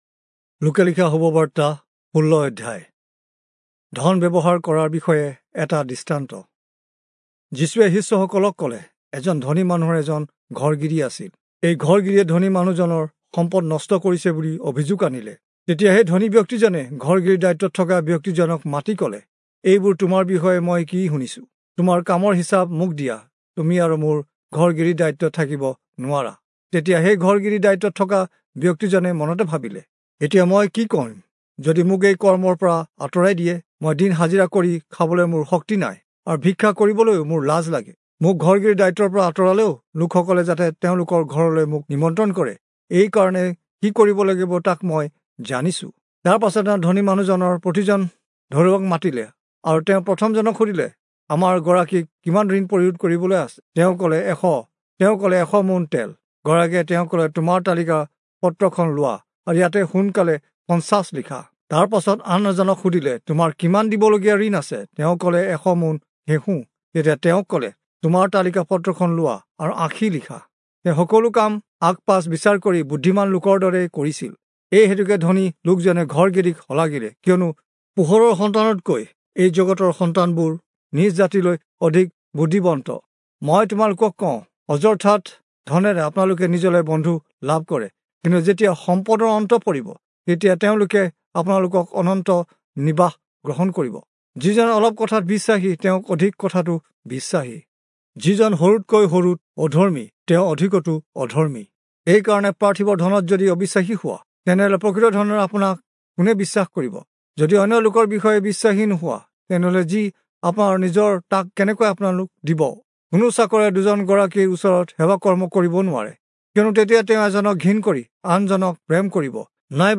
Assamese Audio Bible - Luke 4 in Ervpa bible version